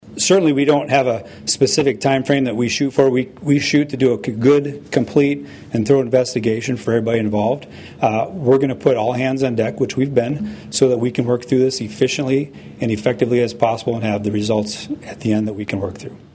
Department of Public Safety Secretary Craig Price answered questions at a news conference and says multiple agencies are involved.